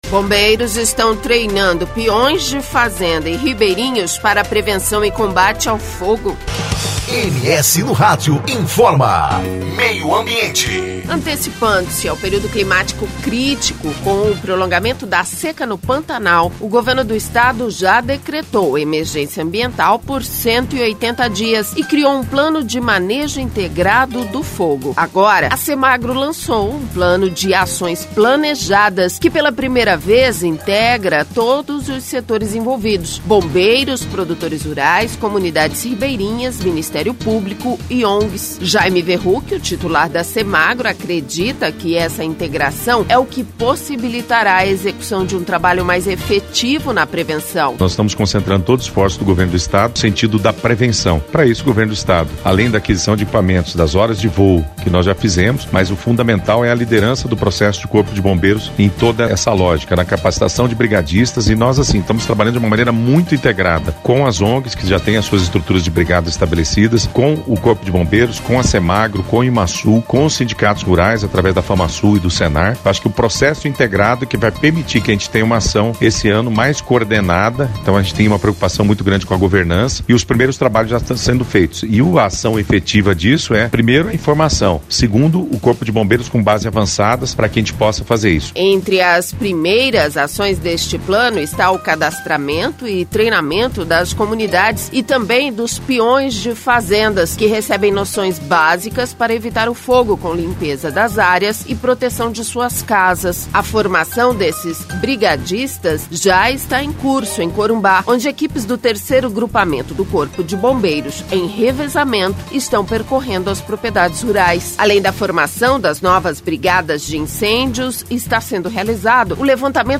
Confira as informações com a repórter